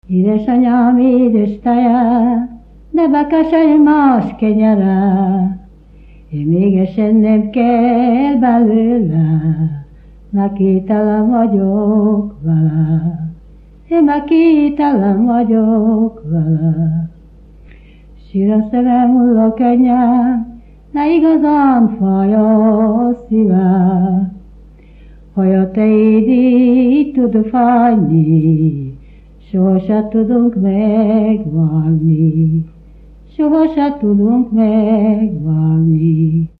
Erdély - Csík vm. - Gyimesfelsőlok
Műfaj: Keserves
Stílus: 3. Pszalmodizáló stílusú dallamok
Kadencia: 5 (b3) 1 1